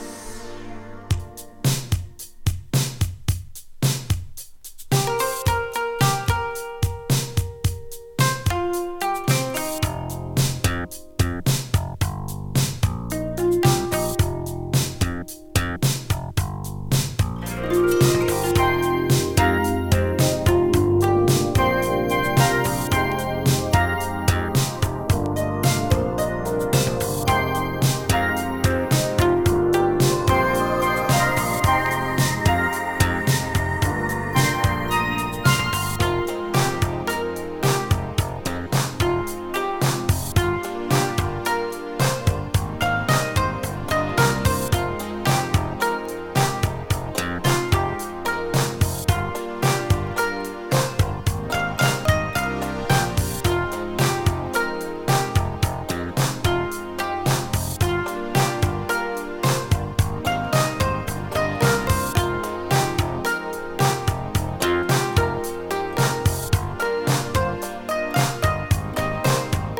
琴や尺八を大々的に取り入れた日系フュージョン・グループ
琴とエレクトロニクスの混ざり具合が絶妙にマッチした
シンセポップ